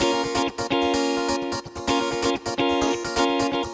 VEH3 Electric Guitar Kit 1 128BPM
VEH3 Electric Guitar Kit 1 - 4 A# min.wav